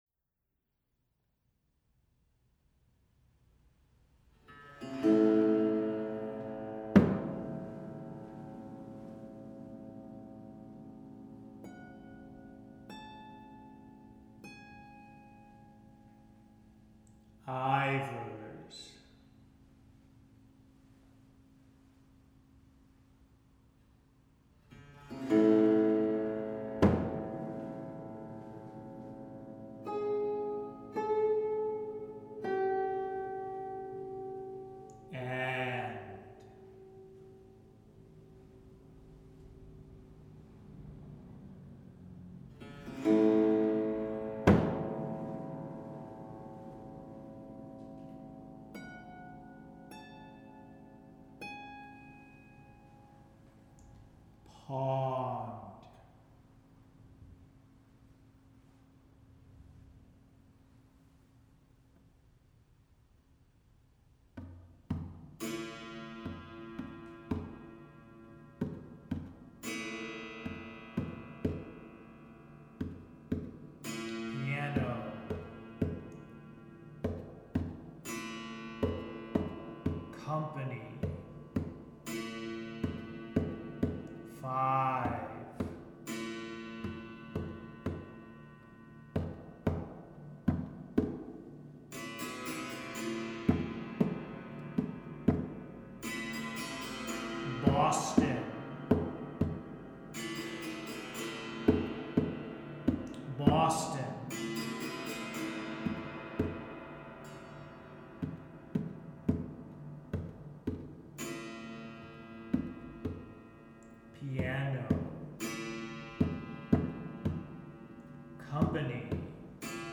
Dropped piano